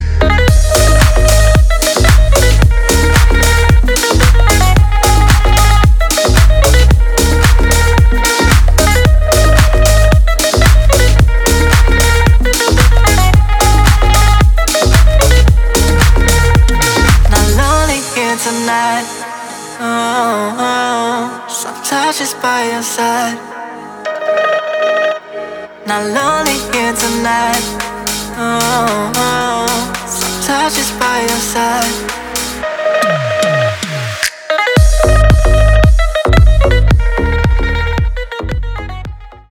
• Качество: 320, Stereo
deep house
восточные мотивы
мелодичные
заводные
dance
Electronic